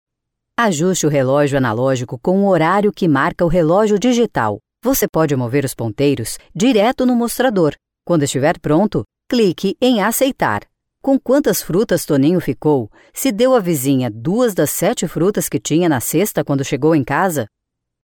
Voces profesionales brasileñas.
locutora brasil, brazilian voice talent